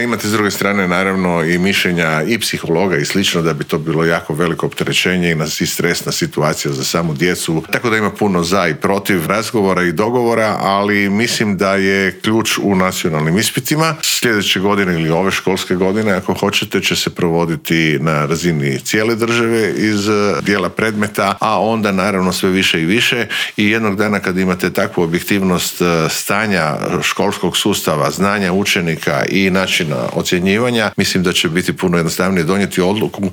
ZAGREB - U ponedjeljak će zvono označiti početak nove školske godine, a prije nego što se školarci vrate pred ploču, pred mikrofon Media servisa u Intervjuu tjedna stao je ministar znanosti i obrazovanja Radovan Fuchs.